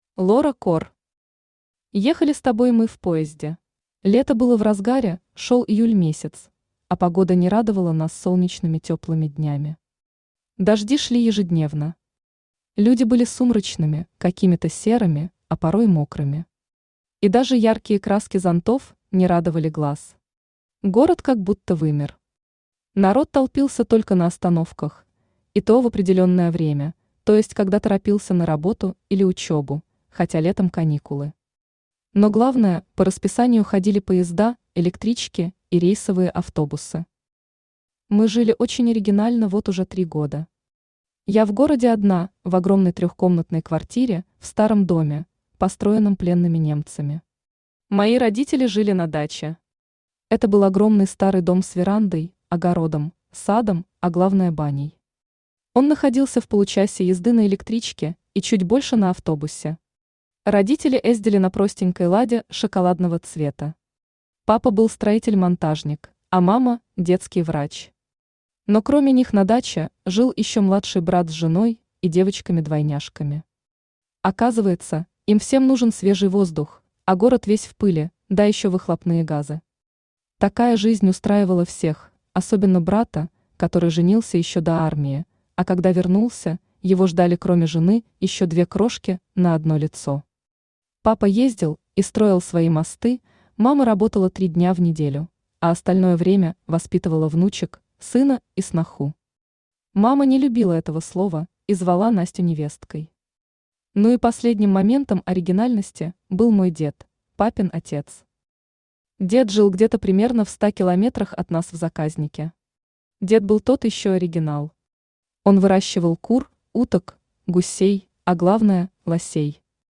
Автор Лора Кор Читает аудиокнигу Авточтец ЛитРес.